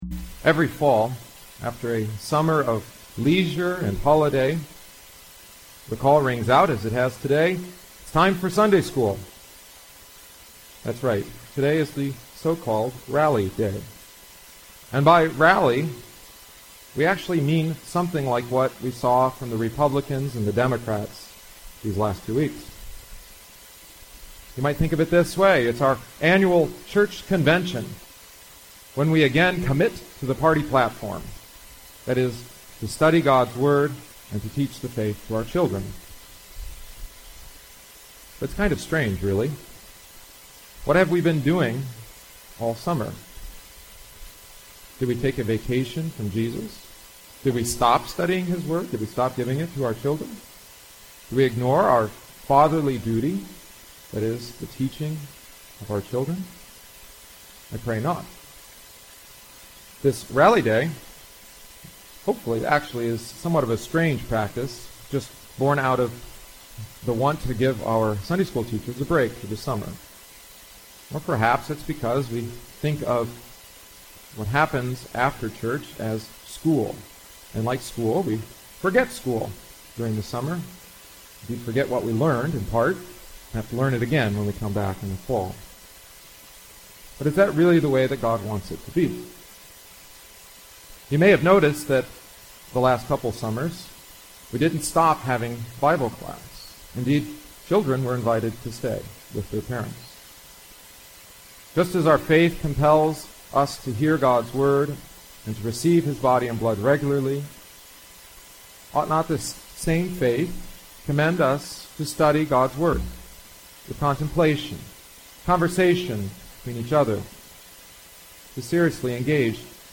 * Despite the audio quality, I’m including it because of the changes I made as this text was preached.